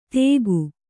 ♪ tēgu